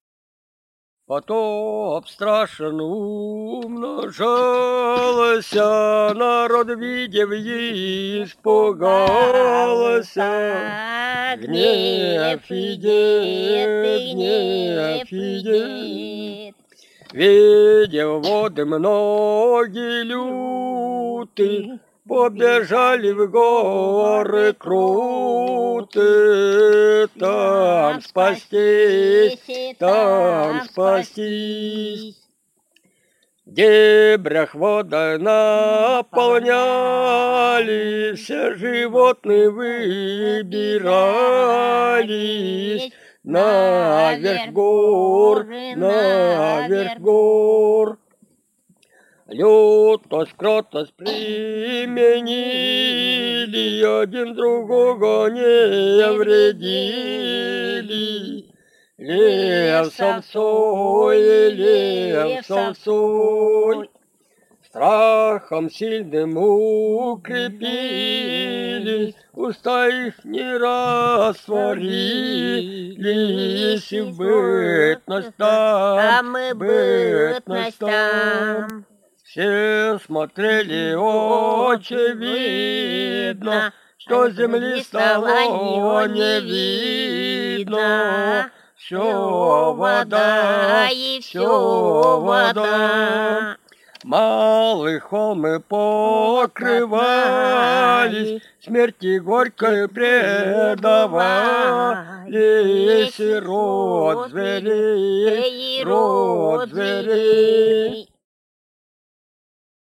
Русские песни Алтайского Беловодья «Потоп страшнен умножался», духовный стих.
с. Тихонька Горно-Алтайская АО, Алтайский край